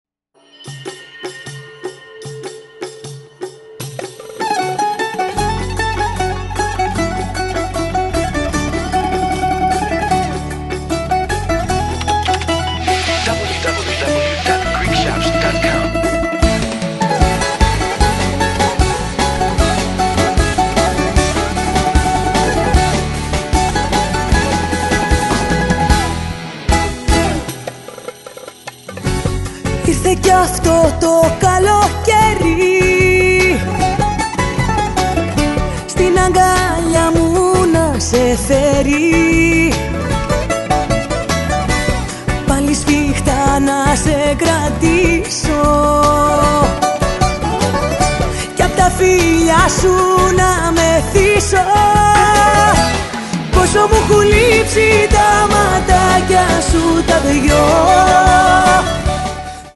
modern laika songs